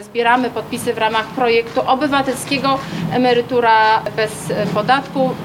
Mówi Urszula Pasławska.